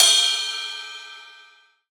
• Long Trap Ride Cymbal G# Key 04.wav
Royality free ride cymbal sound sample tuned to the G# note.
long-trap-ride-cymbal-g-sharp-key-04-GEM.wav